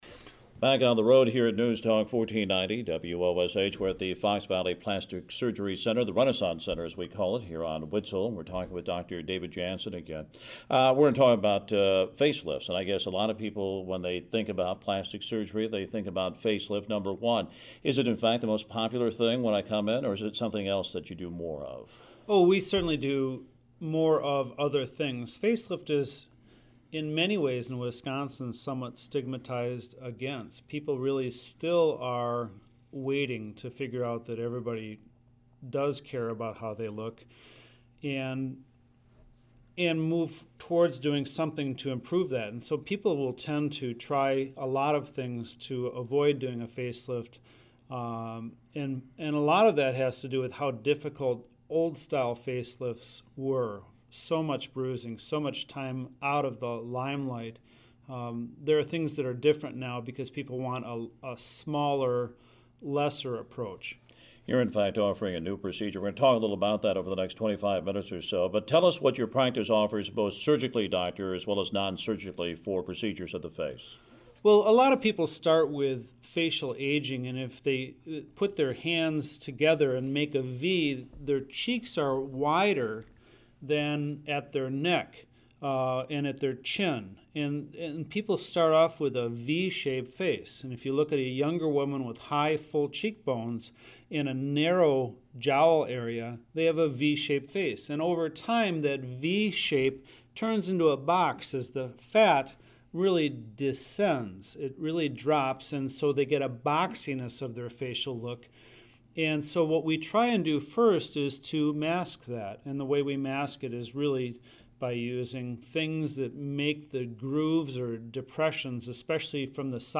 Radio Interview Archives
Several members of our cosmetic surgery team have shared their expertise on the radio.